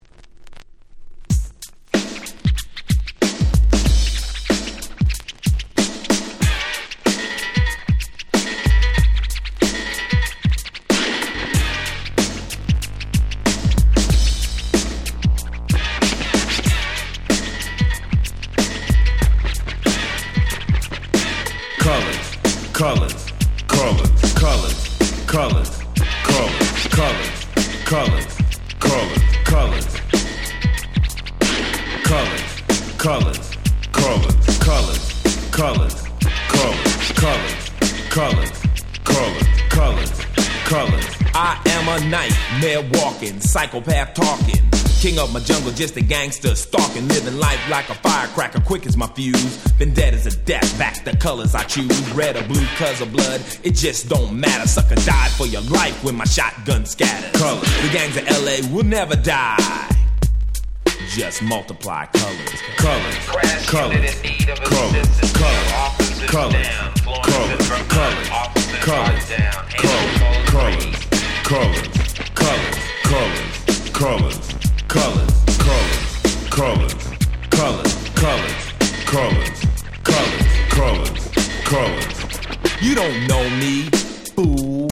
88' Super Hit Hip Hop !!
アイスティー Boom Bap ブーンバップ